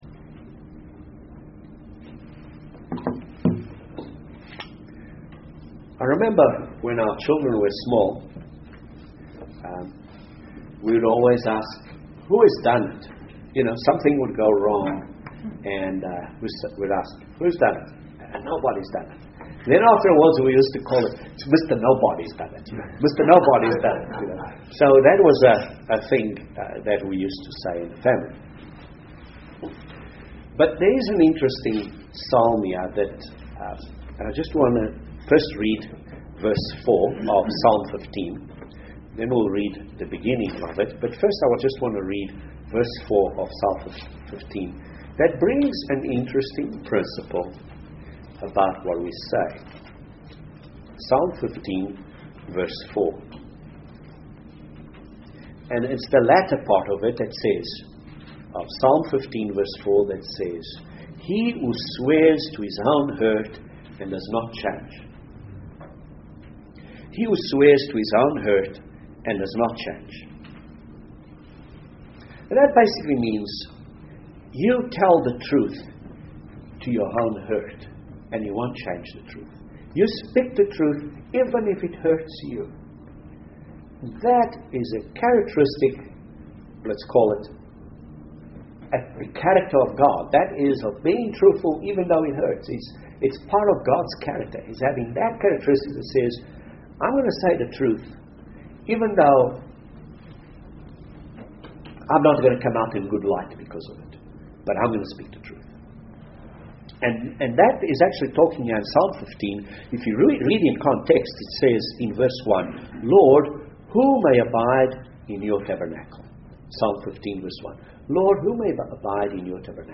Principles to develop Godly Character UCG Sermon Transcript This transcript was generated by AI and may contain errors.